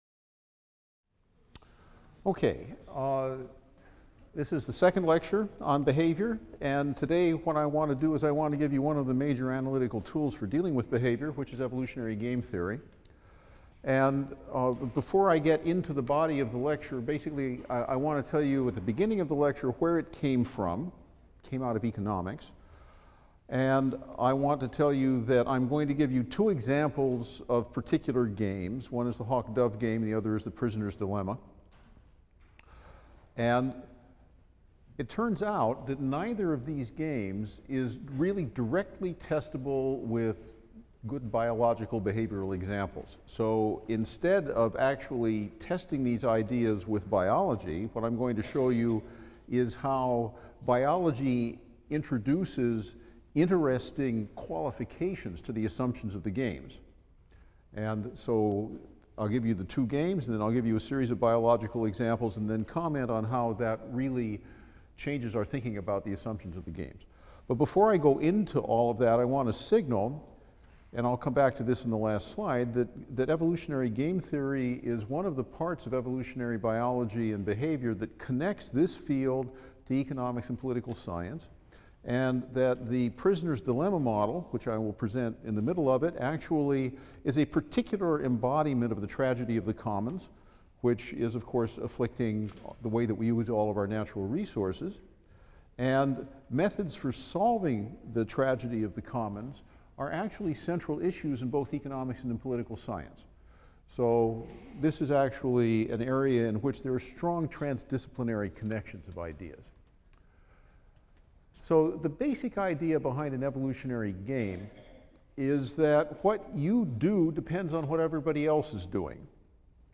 E&EB 122 - Lecture 33 - Evolutionary Game Theory: Fighting and Contests | Open Yale Courses